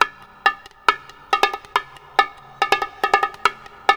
BONG 02.AI.wav